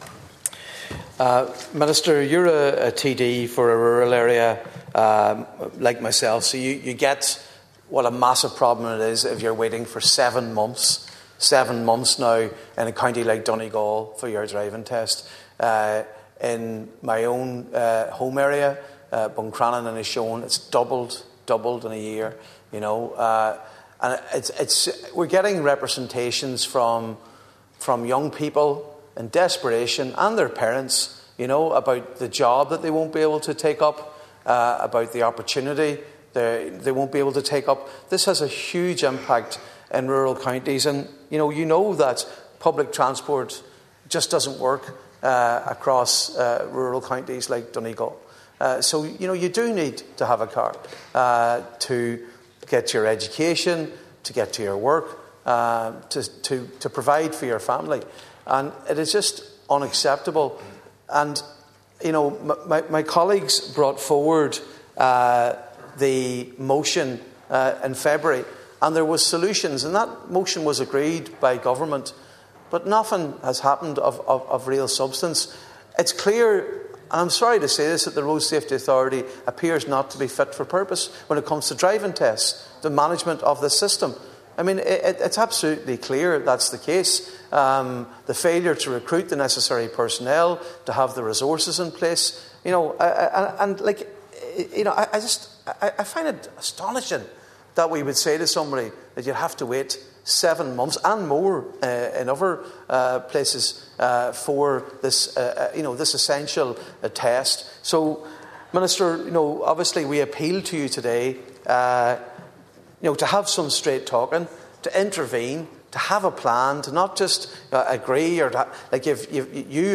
The plight of learner drivers in Donegal was heard by the Dáil chamber yesterday during a discussion on the lengthy wait times faced by those due to sit their driving test.
Deputy Mac Lochlainn questioned if the Road Safety Authority is up to the job in dealing with the backlog: